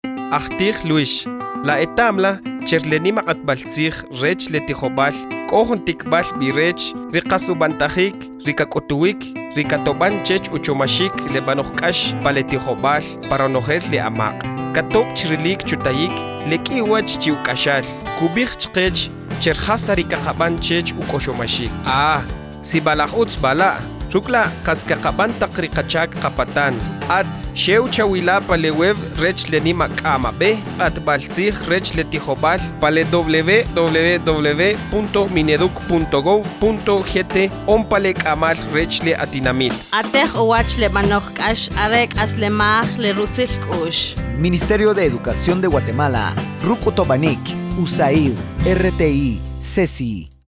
• Spot de radio idiomas mayas.